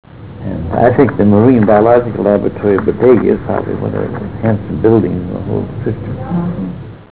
115Kb Ulaw Soundfile Hear Ansel Adams discuss this photo: [115Kb Ulaw Soundfile]